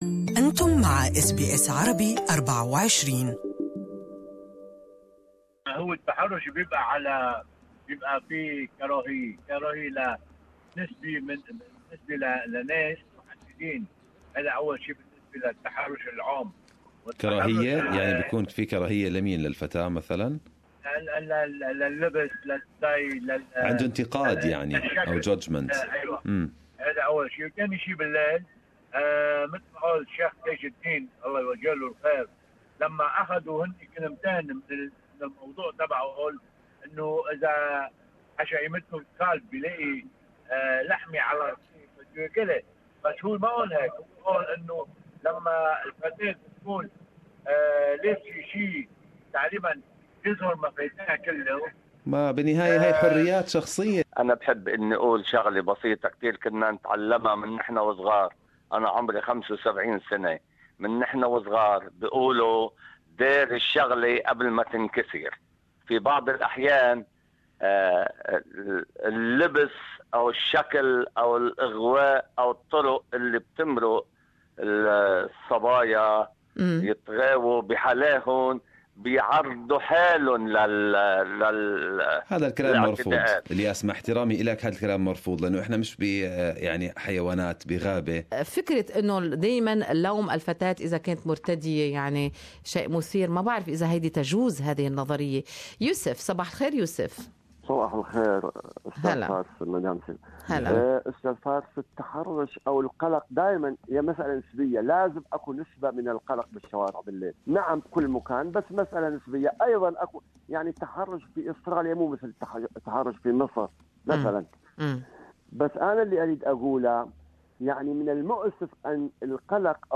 Talkback: What's behind the sexual harrasment incidents in Australia?
Good Morning Australia: talkback segement about causes of sexual harrasement in Australia.